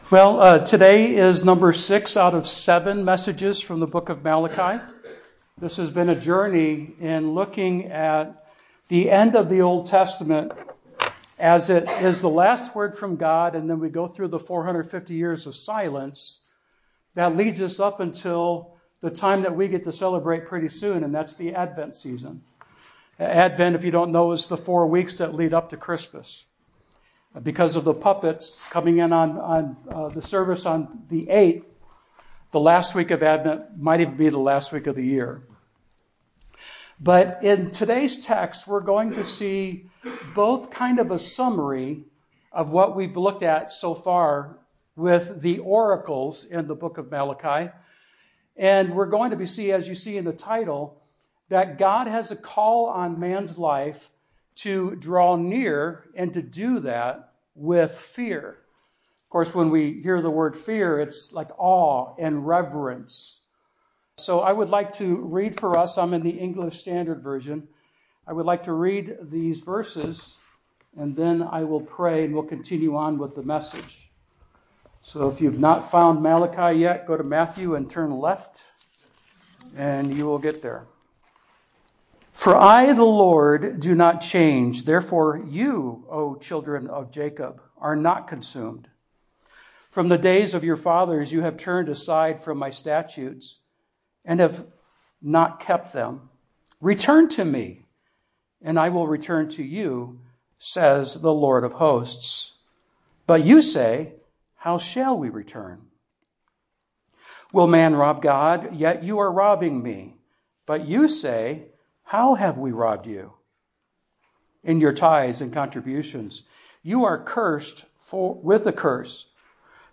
The Power of God’s Rebuke (11a) The Proof of God’s Protection (11b) The devourer rebuked (11a) The destruction thwarted (11b) The delightful harvest (11c) The Promise of God’s Blessings (12) … the sermon ends at 40 minutes and 20 seconds. The following 3 minutes is an introduction to observing the Lord’s Supper.